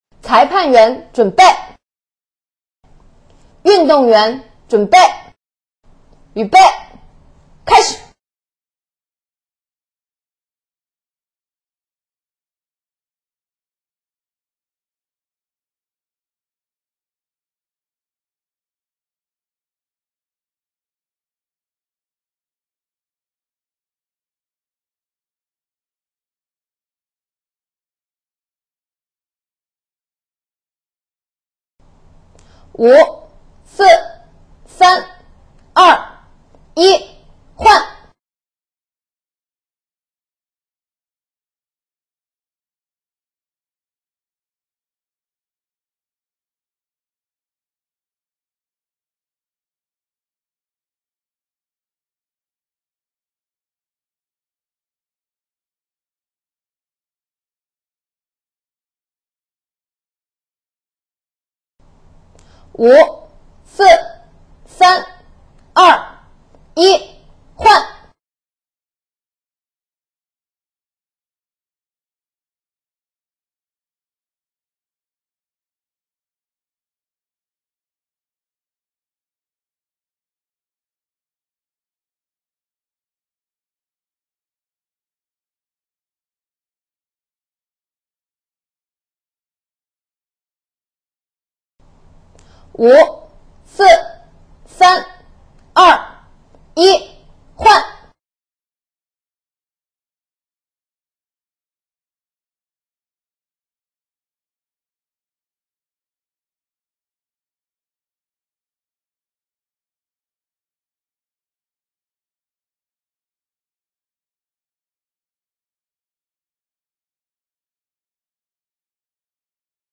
附件8：（4人×30秒）2分钟计时音频.mp3